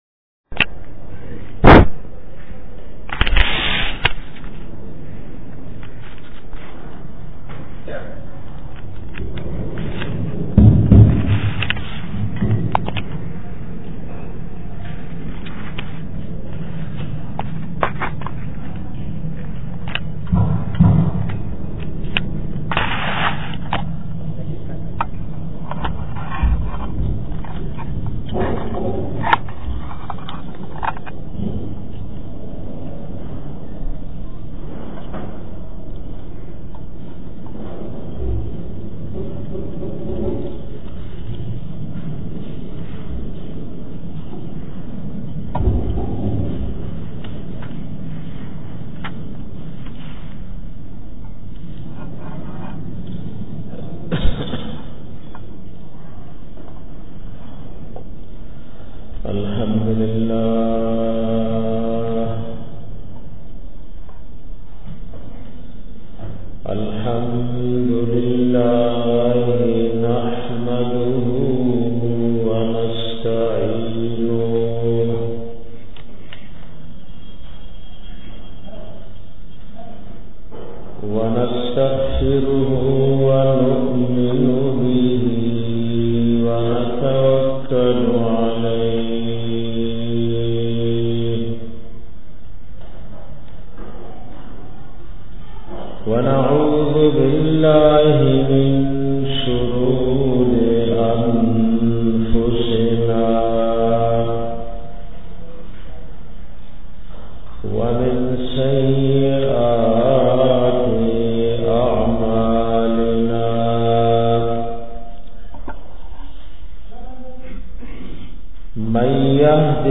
bayan da sheethan da bachao asbabu ka 3